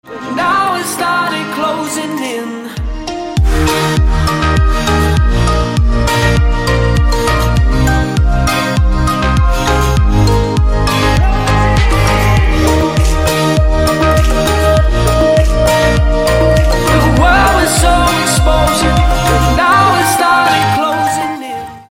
deep house
dance
tropical house